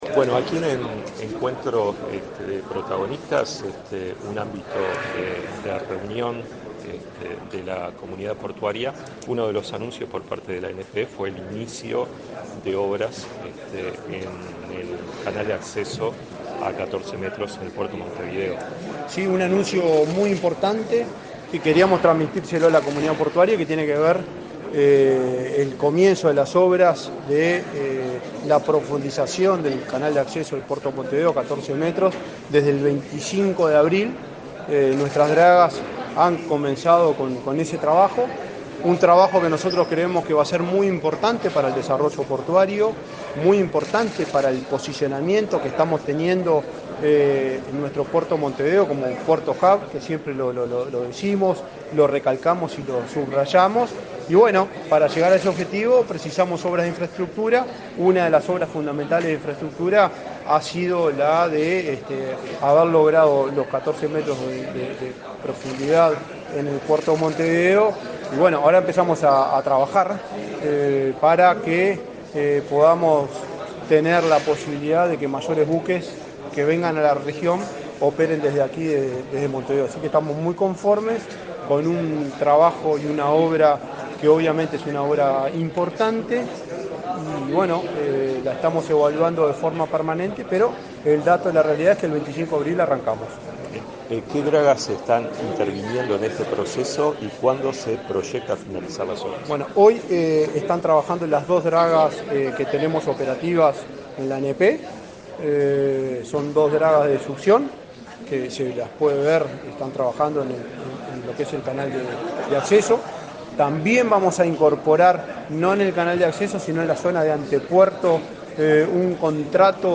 Entrevista al presidente de la ANP, Juan Curbelo